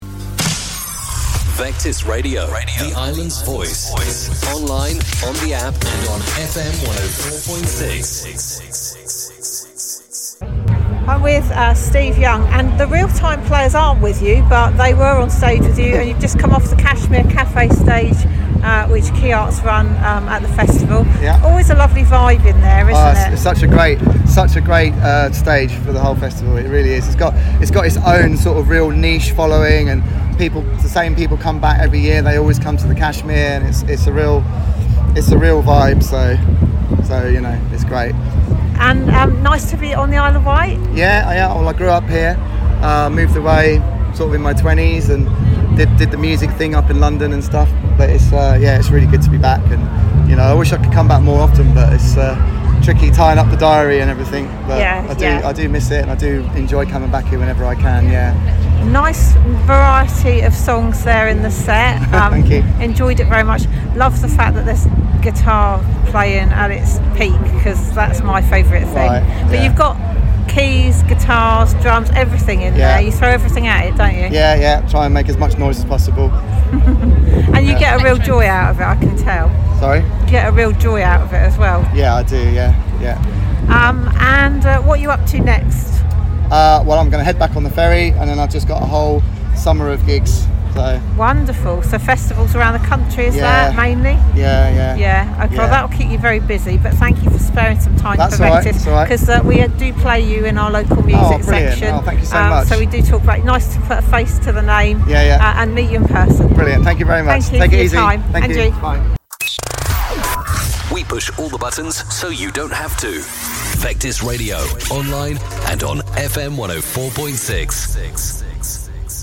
IW Festival 2025: Interview